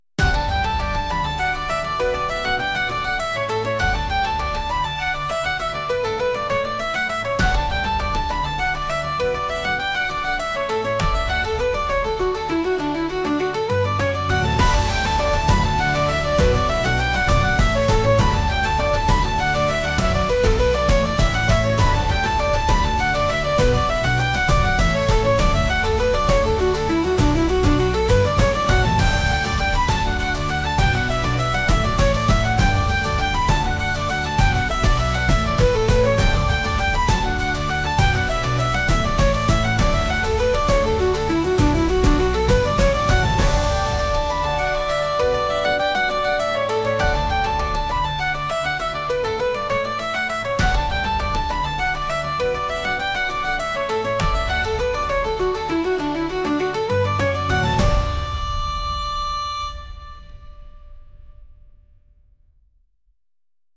どこかへ出かけるようなケルト曲です 音楽素材（MP3）ファイルのダウンロード、ご利用の前に必ず下記項目をご確認ください。